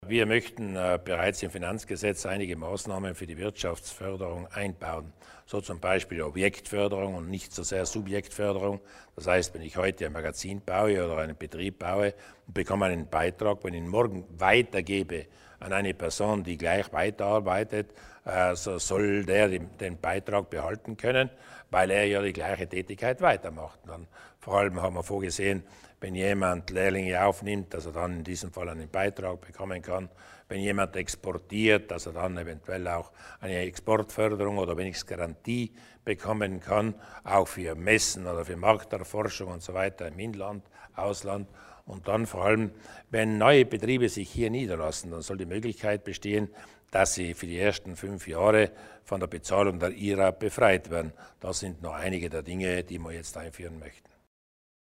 Landeshauptmann Durnwalder über die den Maßnahmen zur Wirtschaftsförderung